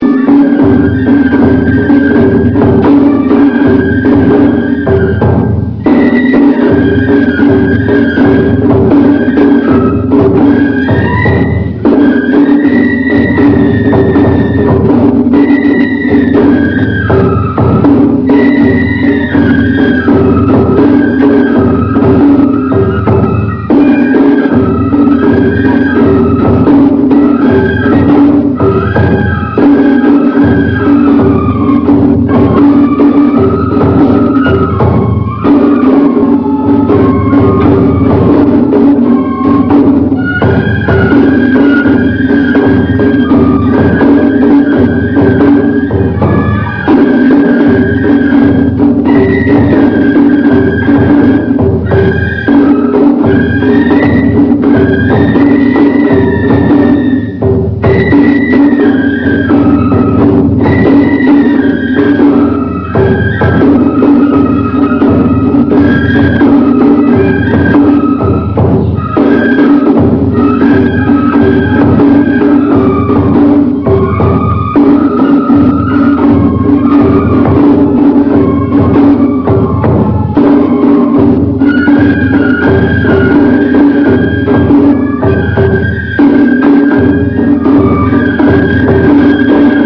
生青竹の音色をご鑑賞下さい。
この写真では見えませんが、実際に演奏している 笛の中で、笹の枝が付いたままの笛を吹く方が、マイクの前に立つ人（写真左から３人 目）と、その後ろで４人ほどおりました。